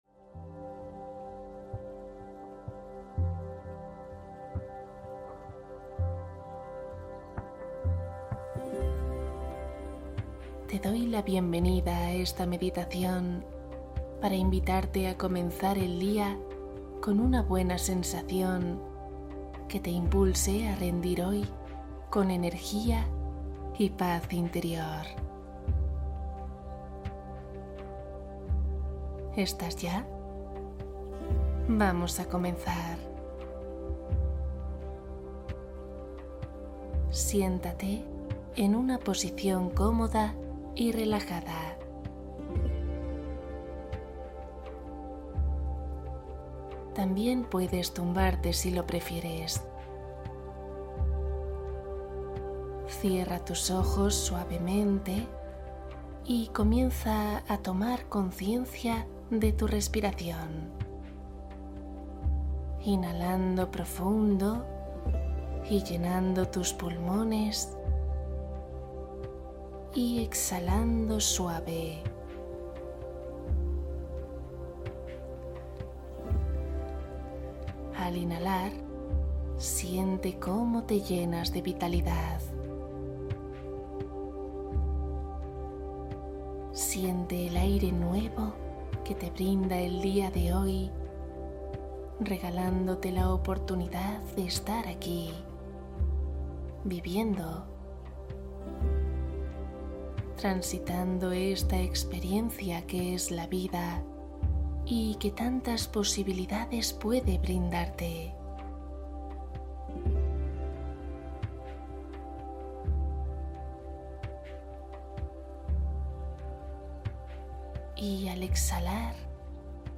Cuento para dormir Relajación profunda para una noche de descanso